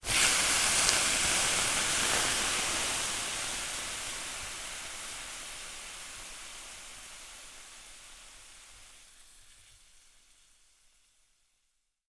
Звуки массажа, СПА
Сауна, вода льется на камни и угли, слышно шипение